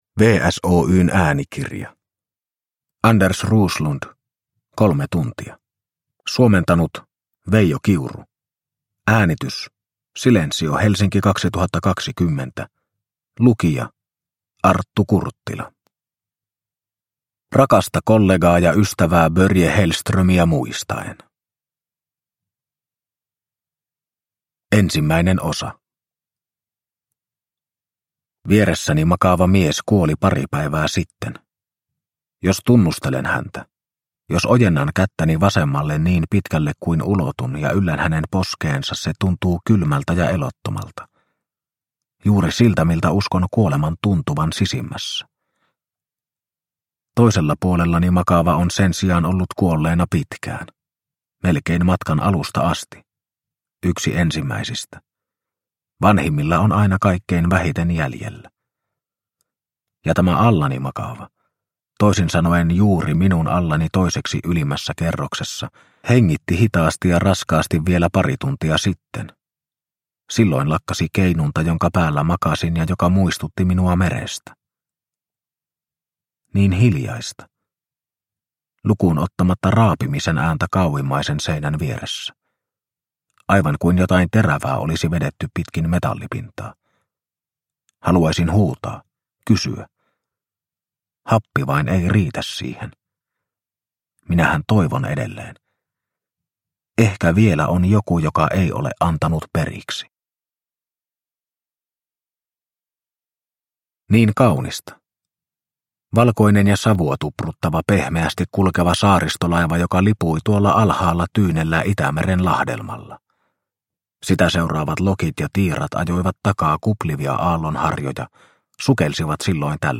Kolme tuntia – Ljudbok – Laddas ner